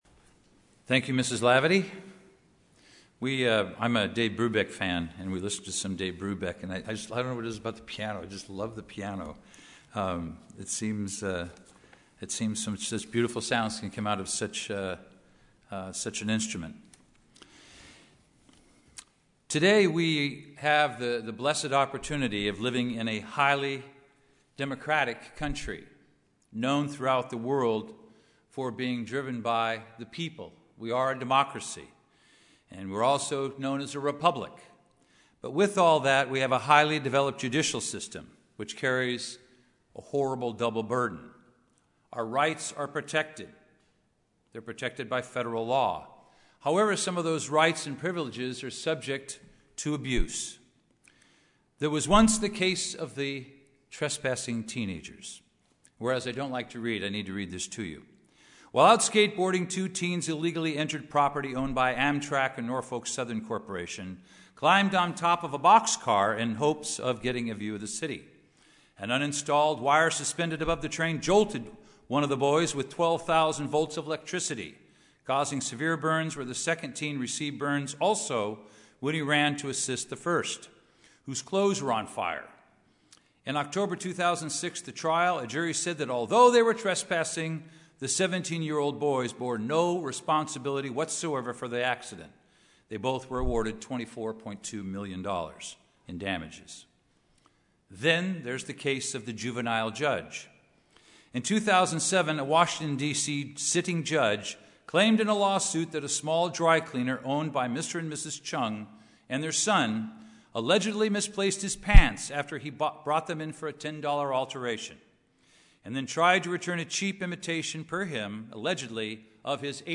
Given in Los Angeles, CA
UCG Sermon Studying the bible?